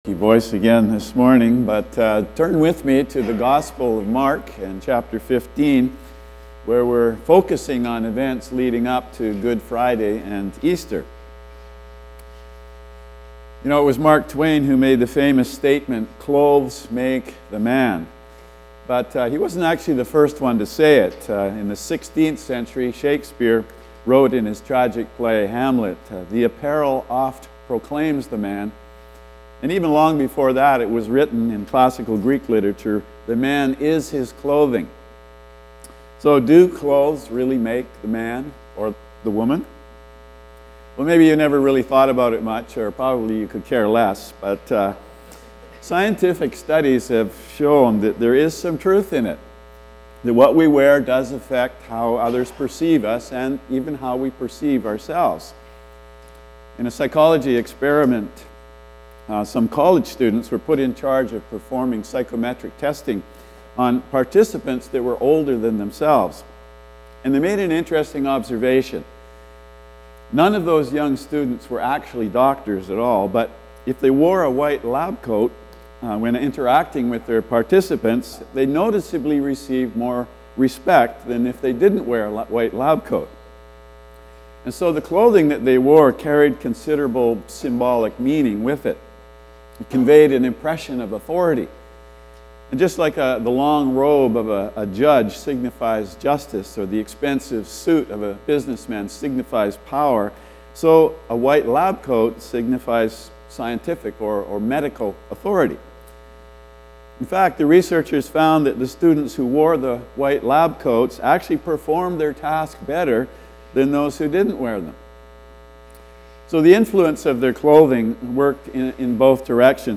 Pre-Easter Message: The Clothes of the King – Calvary Baptist Church of Gibsons